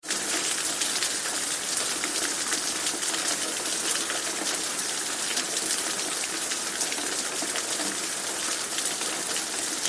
raining.mp3